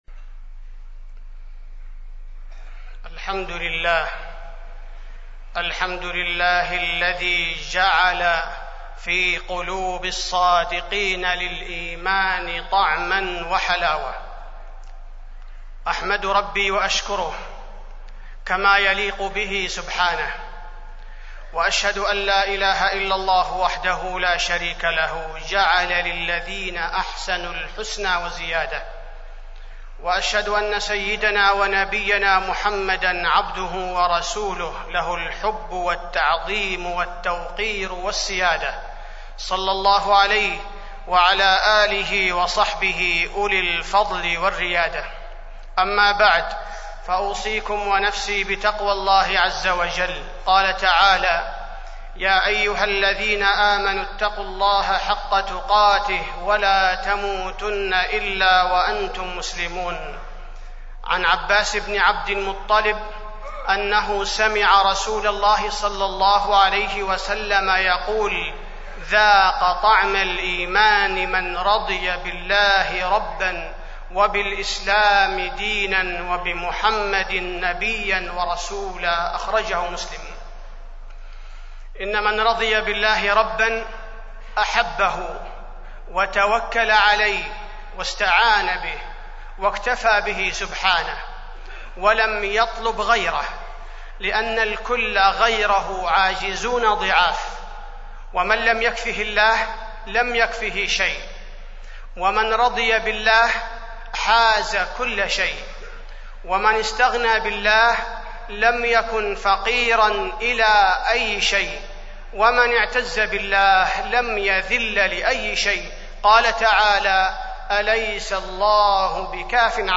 تاريخ النشر ١٤ ذو القعدة ١٤٢٦ هـ المكان: المسجد النبوي الشيخ: فضيلة الشيخ عبدالباري الثبيتي فضيلة الشيخ عبدالباري الثبيتي حلاوة الإيمان